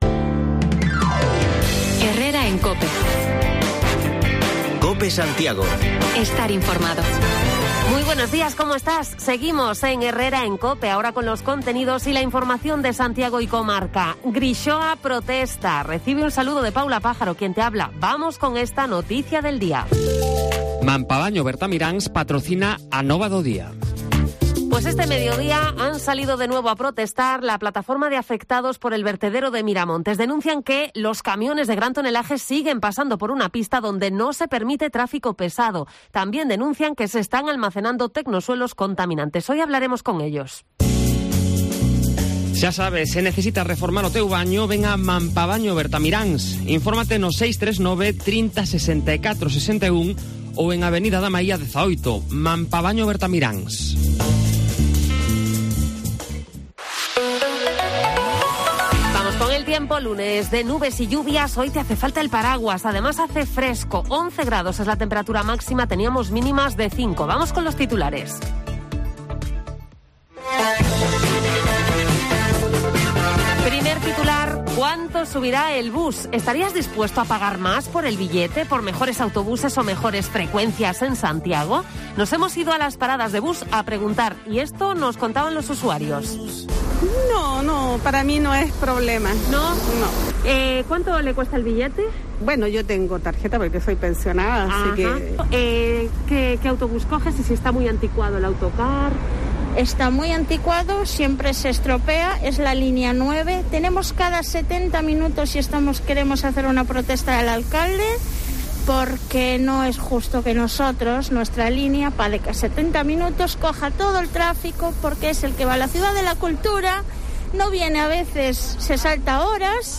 Bajamos los micrófonos de Cope Santiago a la calle para conocer la opinión de los usuarios sobre el transporte urbano y la posibilidad de que suba el precio del autobús. Además, nos acercamos a una floristería y comprobamos que San Valentín tiene tirón en Compostela